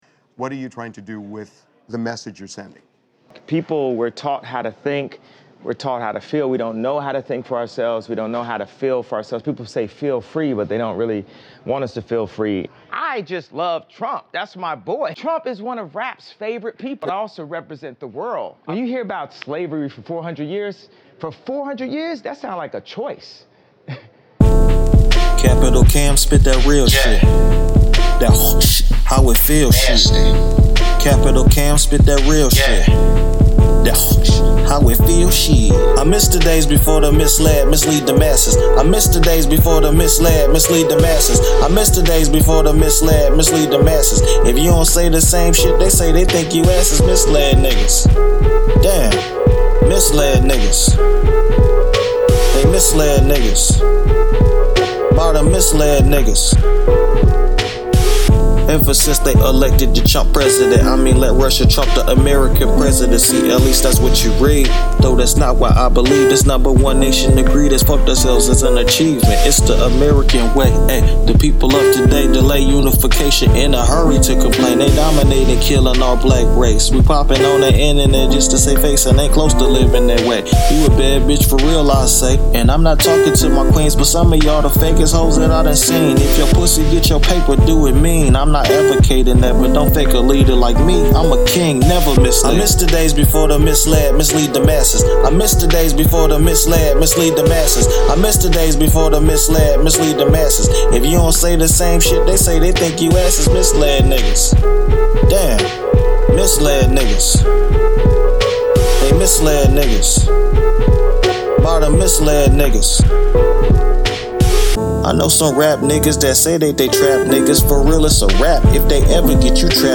Hiphop
Description : Wake up, real rap music